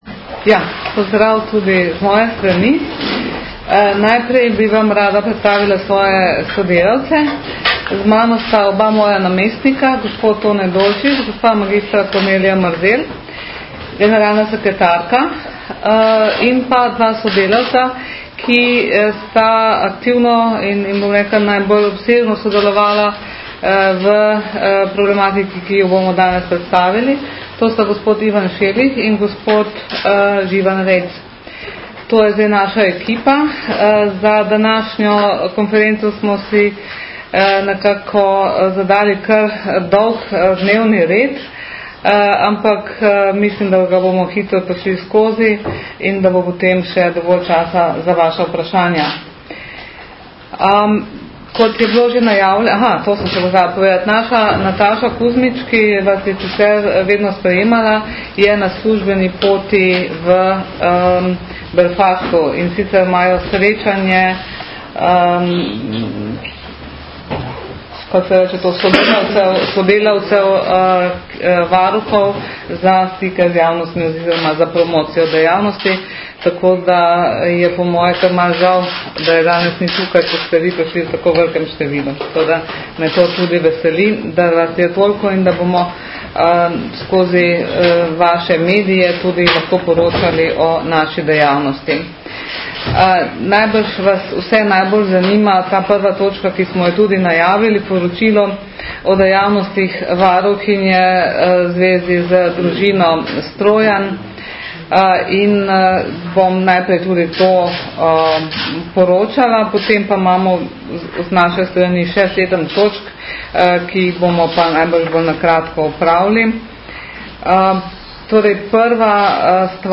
Zvočni posnetek novinarske konference...